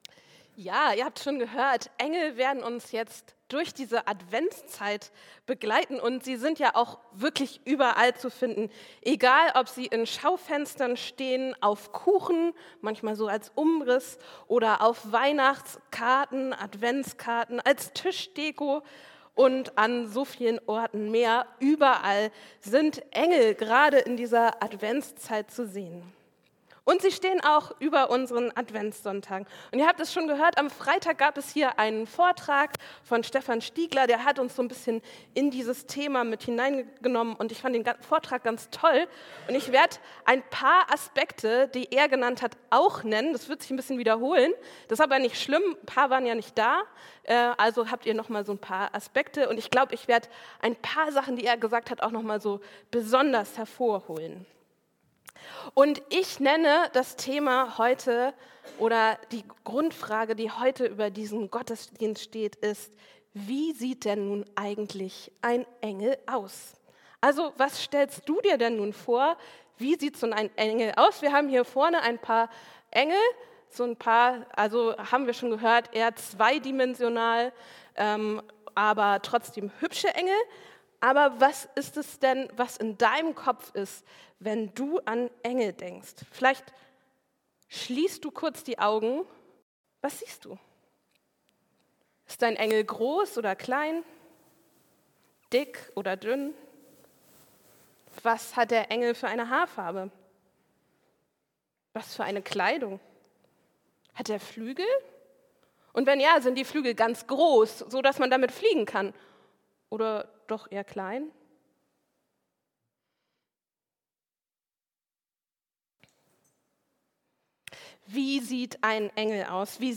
Engel (1/3): Wie sieht ein Engel aus? ~ Christuskirche Uetersen Predigt-Podcast Podcast